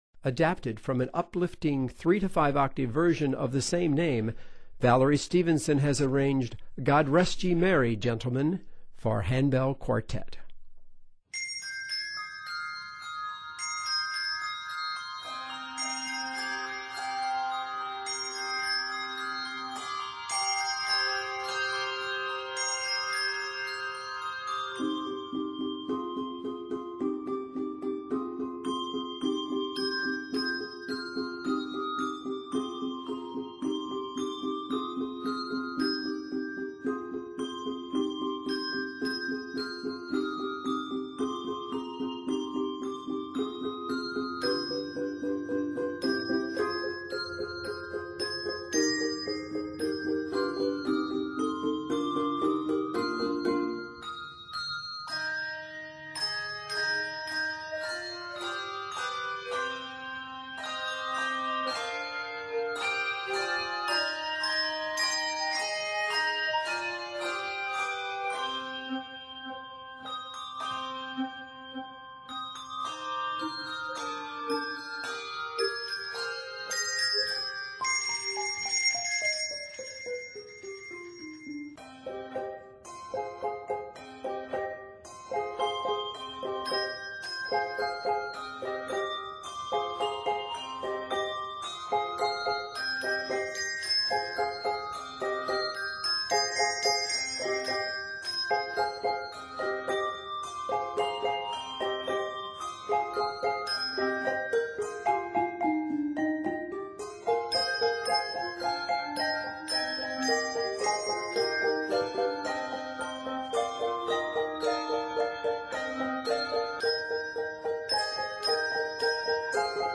It is set in c minor.